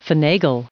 1971_finagle.ogg